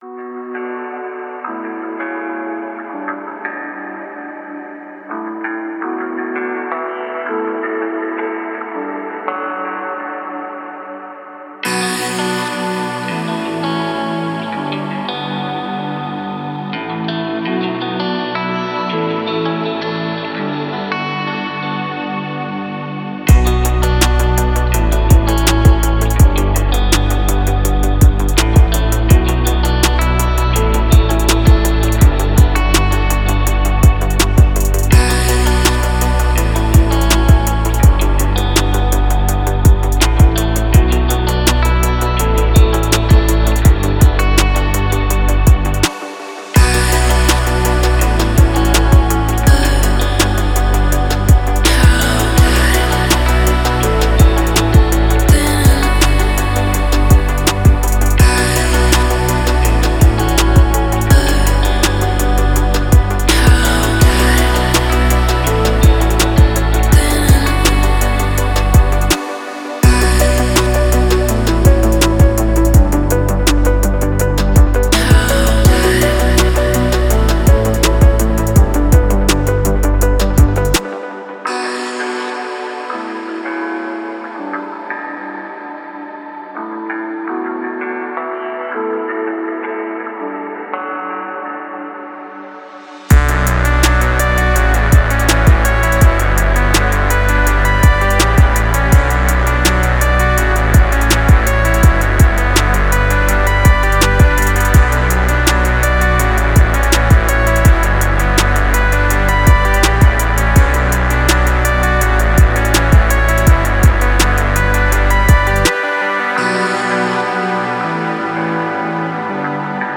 Спокойная музыка
приятная музыка